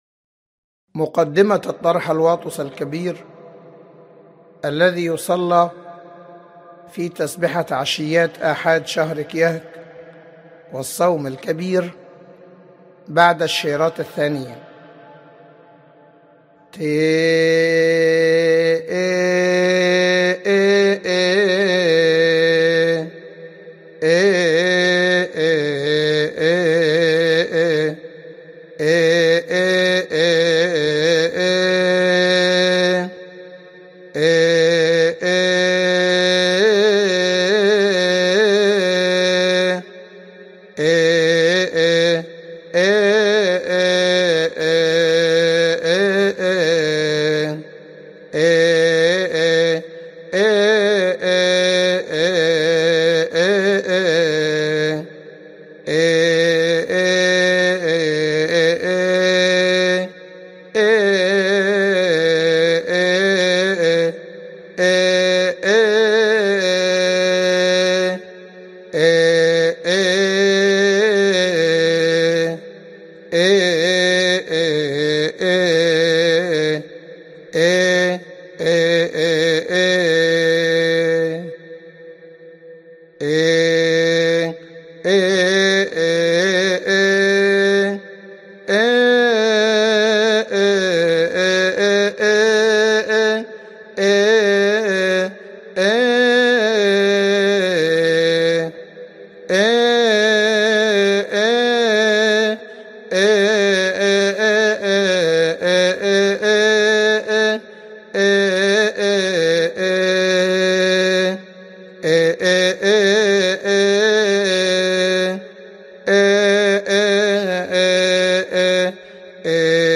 لحن آسشوپى ذيه خين نى إيهوؤو